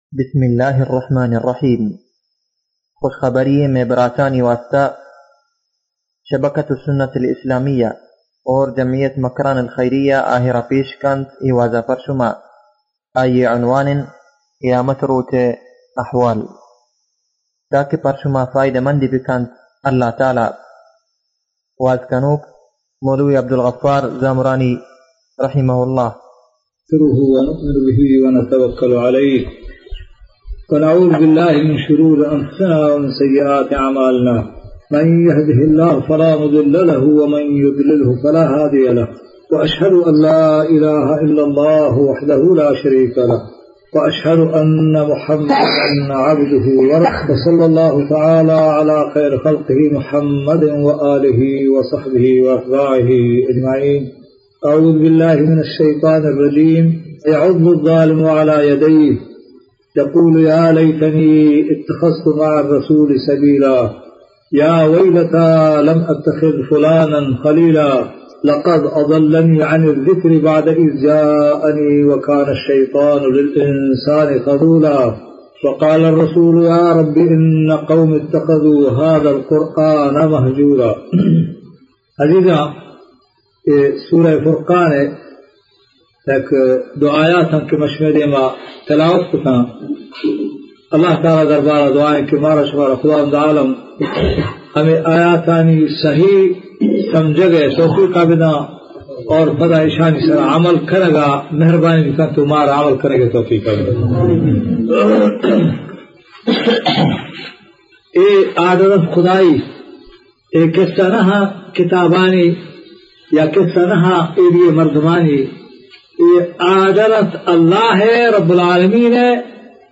أهوال يوم القيامة: في هذه المحاضرة بين الشيخ جانبا من الأهوال التي ستكون في يوم القيامة.